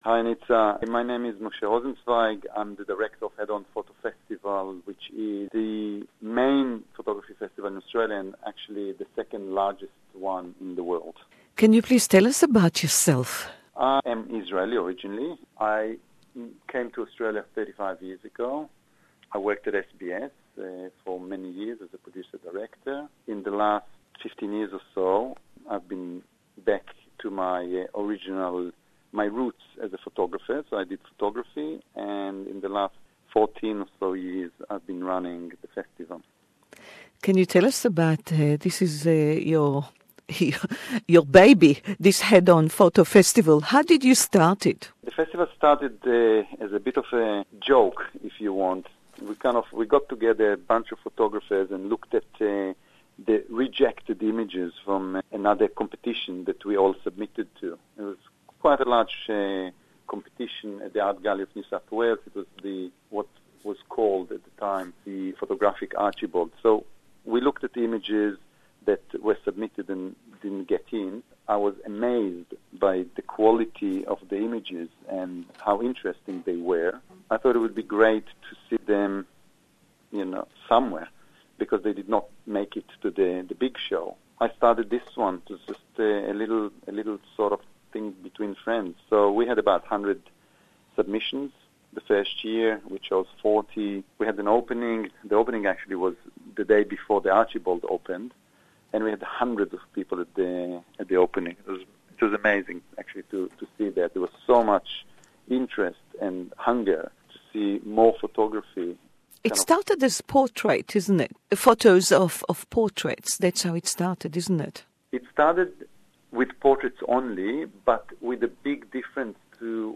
English Interview | SBS Hebrew